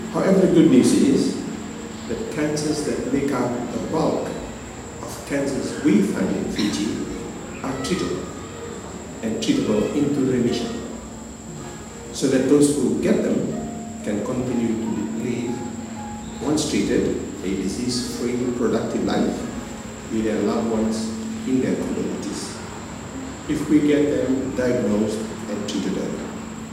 Chief Medical Adviser, Doctor Jemesa Tudravu highlighted this at the World Cancer Day – Landmark Lighting at the Grand Pacific Hotel last night.